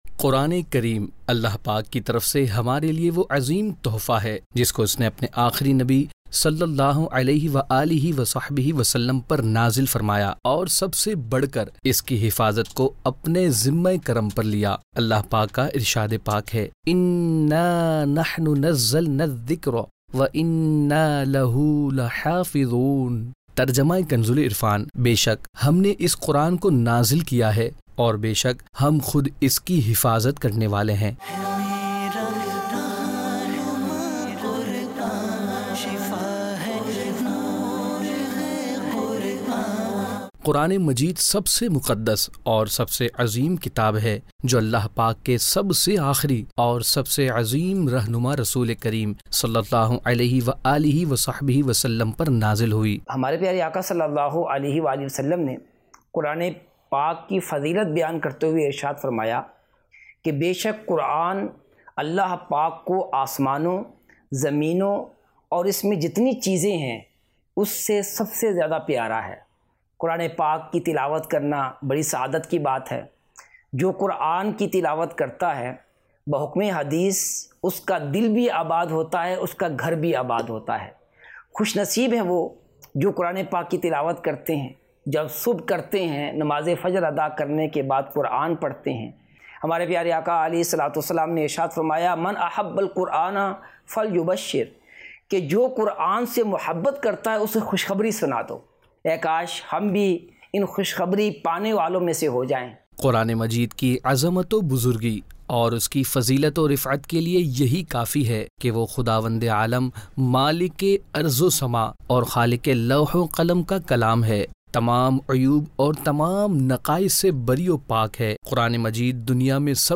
News Clips Urdu - 06 July 2023 - Quran Majeed Furqan e Hameed Kay Fazail Say Mutaliq Report Jul 24, 2023 MP3 MP4 MP3 Share نیوز کلپس اردو - 06 جولائی 2023 - قرآن مجید فرقان حمید کے فضائل سے متعلق رپورٹ